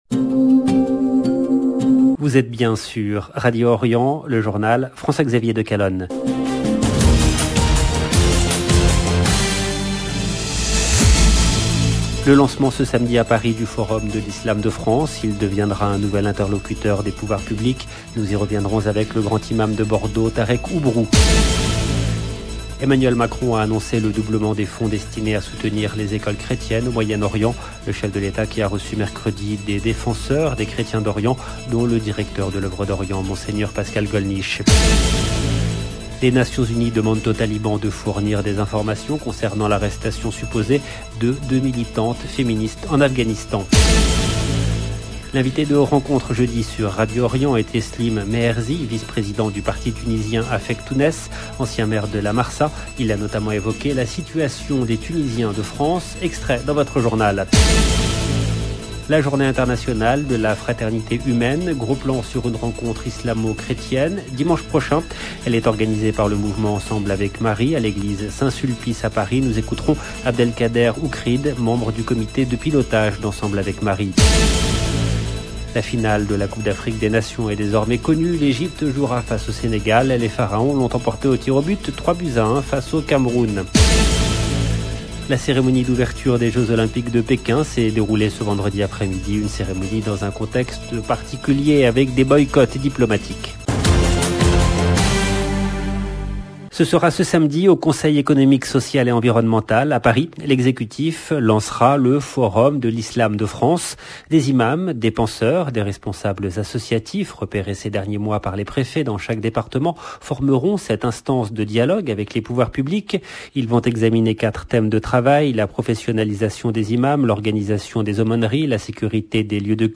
Journal présenté